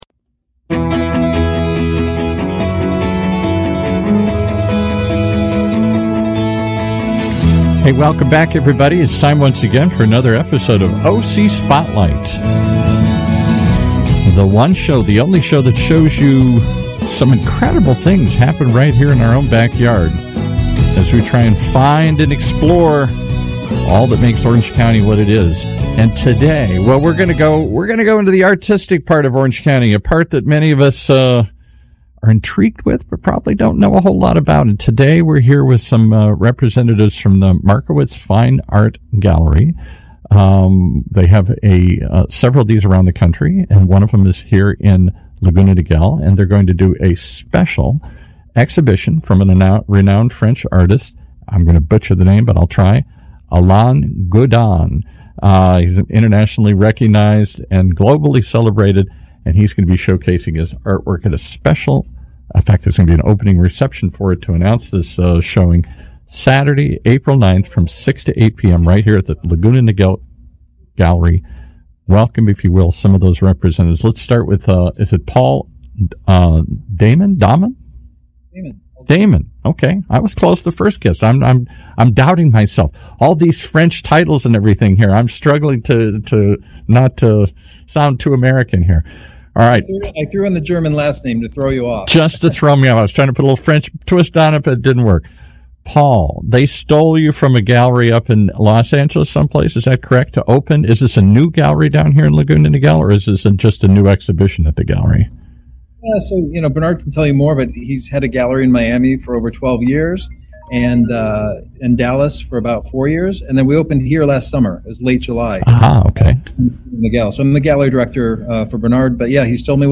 Streaming live from our studios here at the University of California Irvine's BEALL APPLIED INNOVATION CENTER.